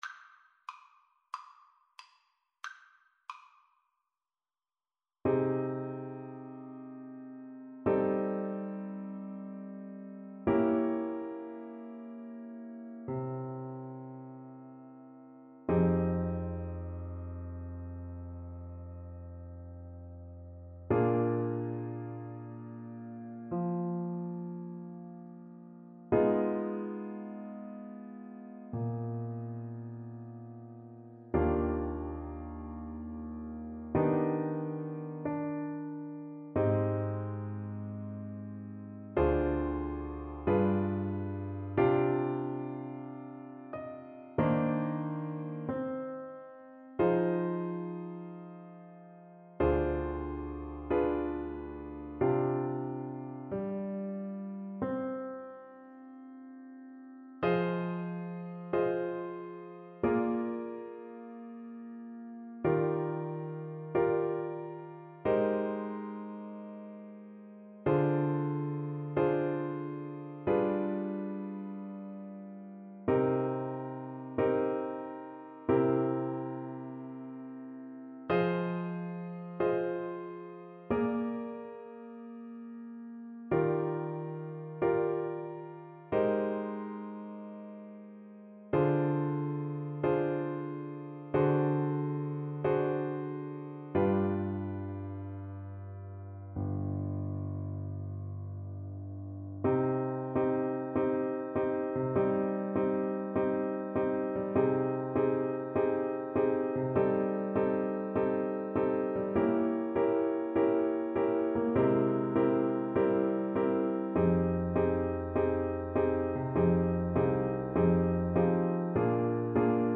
4/4 (View more 4/4 Music)
Andante =c.92
Jazz (View more Jazz French Horn Music)